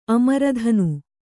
♪ amaradhanu